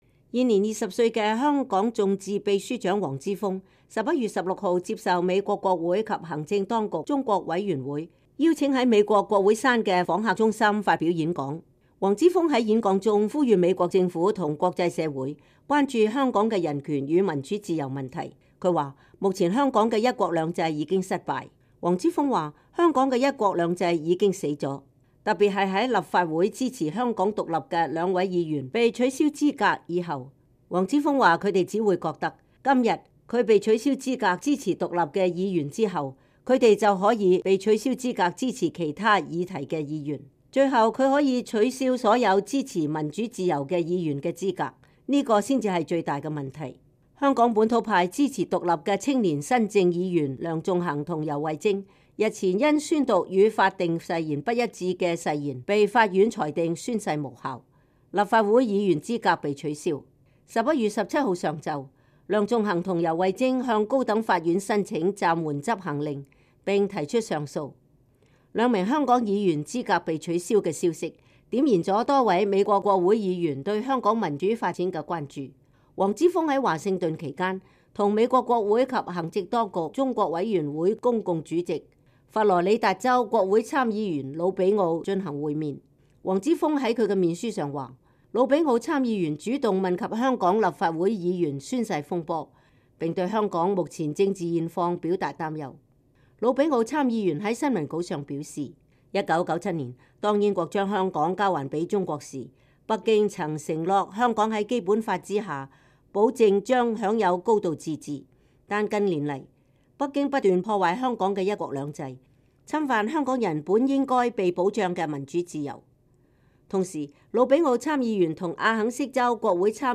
黃之鋒美國國會演講 稱香港一國兩制已失敗
現年20歲的“香港眾志”秘書長黃之鋒11月16日接受美國國會及行政當局中國委員會邀請在美國國會山的訪客中心發表演說，呼籲美國政府和國際社會關注香港的人權與民主自由。他說，目前香港的一國兩制已經失敗。